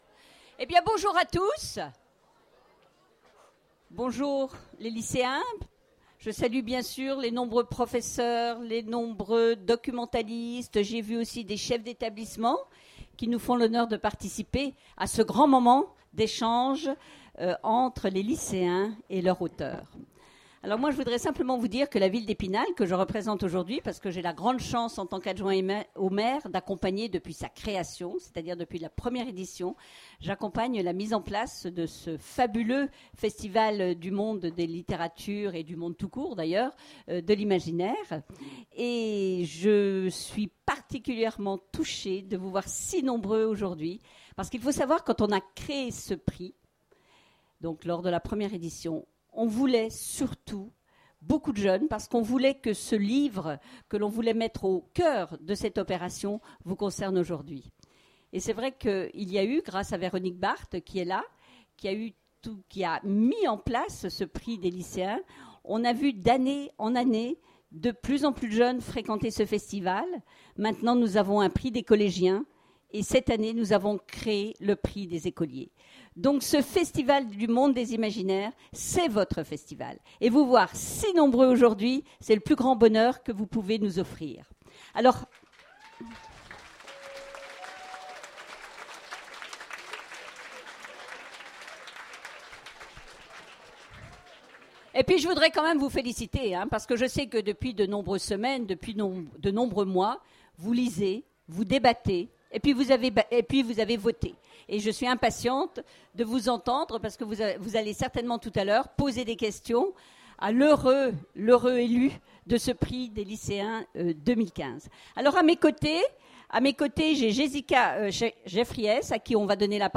Imaginales 2015 : Remise du prix Imaginales des lycéens
Mots-clés Remise de prix Conférence Partager cet article